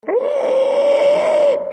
Download Monsters Screaming sound effect for free.
Monsters Screaming